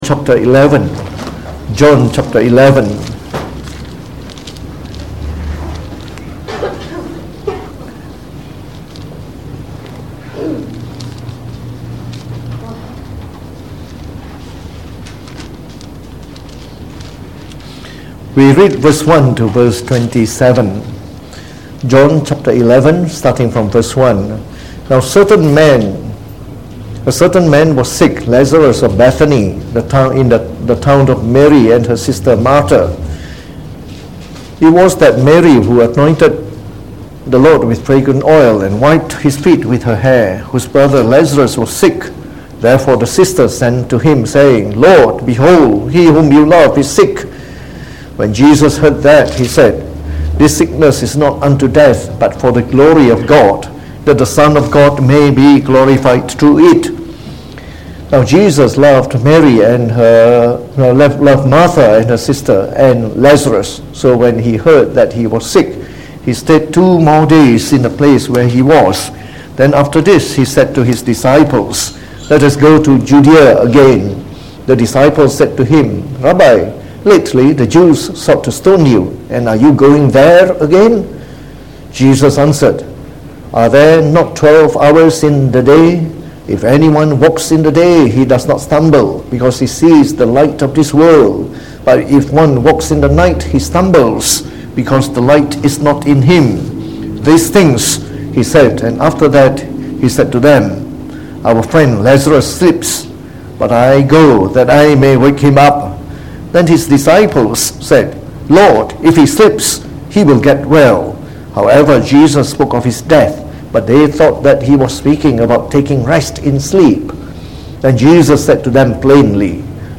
Preached on the 10th February 2019. From our series on the Gospel of John delivered in the Evening Service